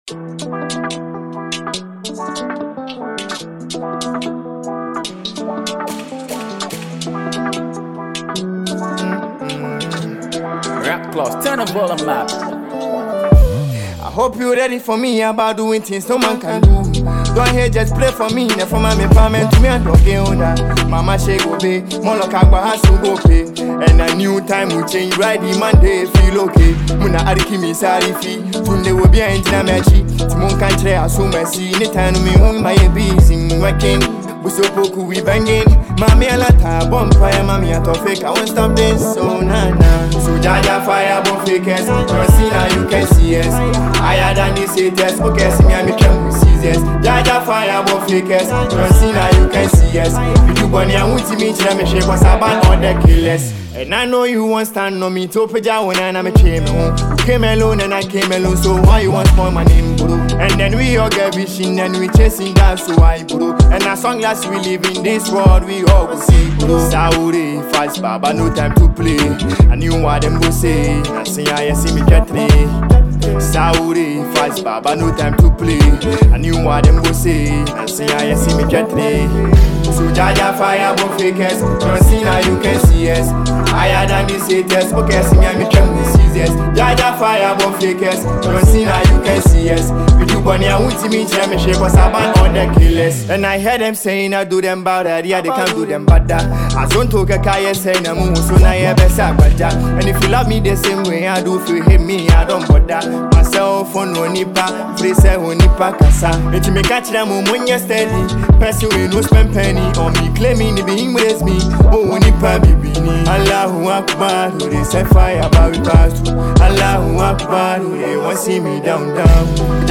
Enjoy this amazing studio track.